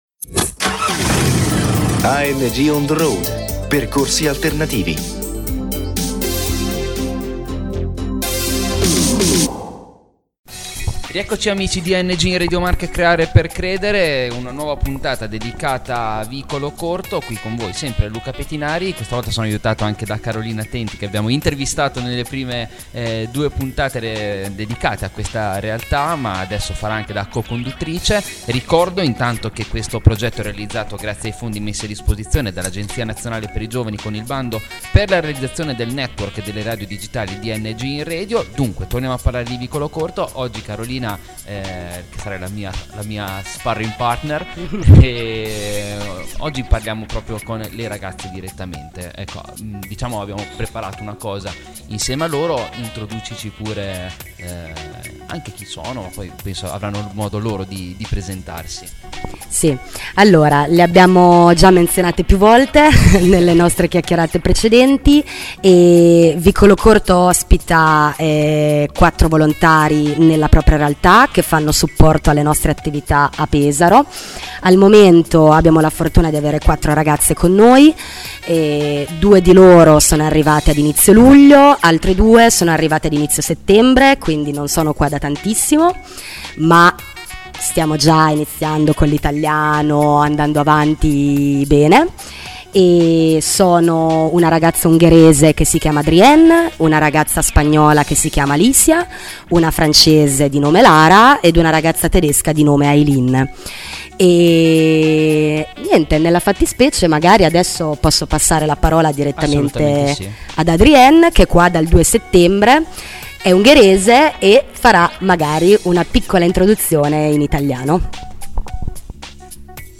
Vicolocorto: dopo due puntate di presentazione dell'associazione, andiamo a sentire le voci e le testimonianze delle volontarie provenienti da tutta Europa sulla loro permanenza in Italia